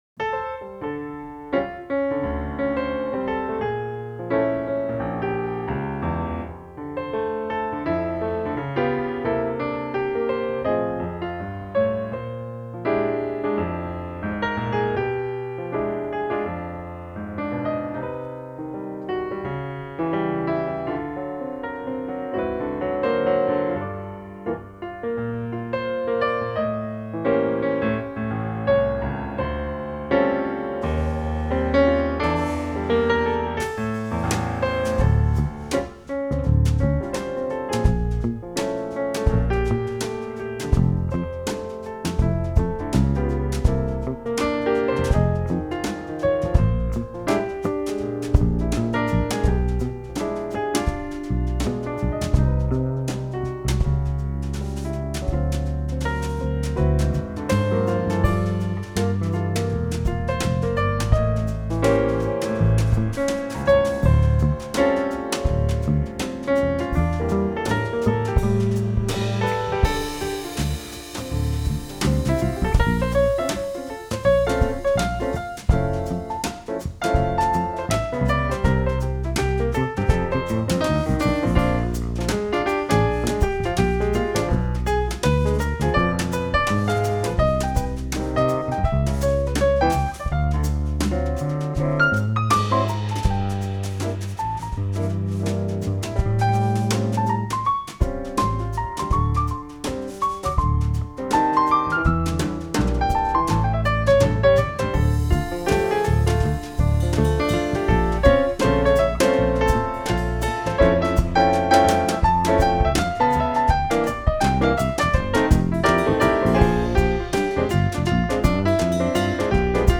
piano
fretless electric bass
drums